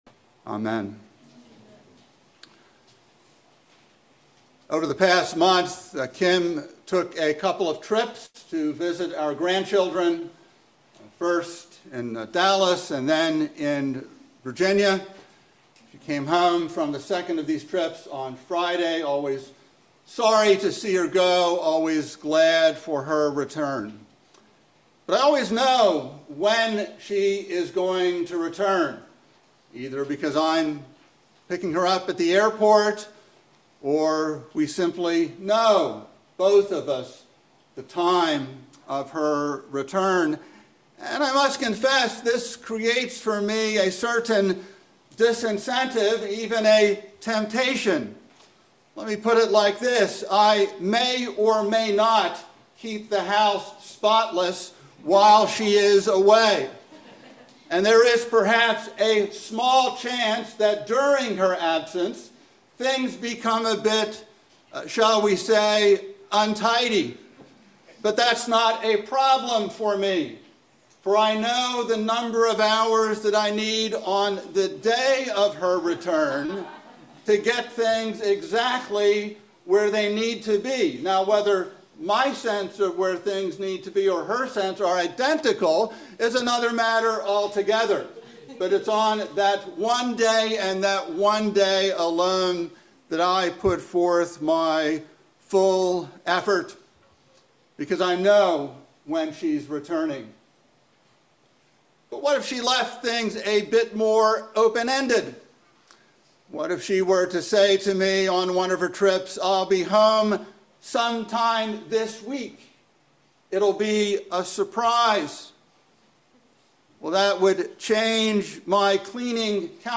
by Trinity Presbyterian Church | Apr 13, 2024 | Sermon